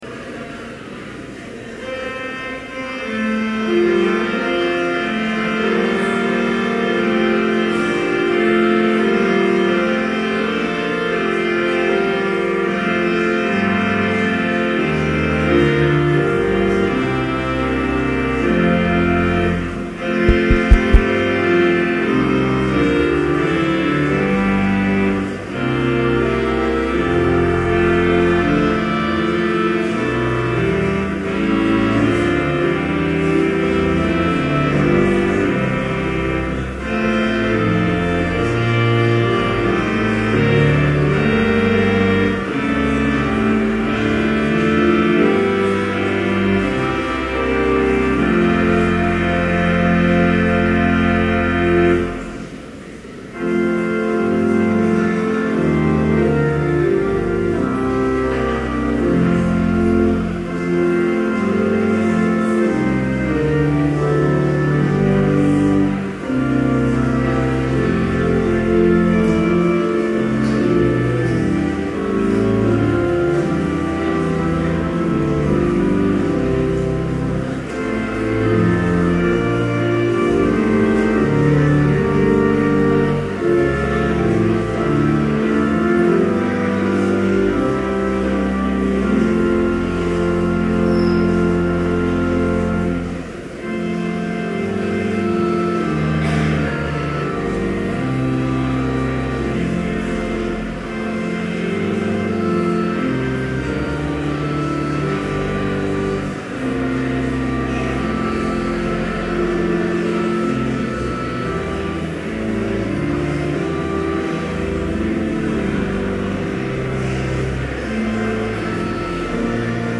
Complete service audio for Chapel - October 3, 2011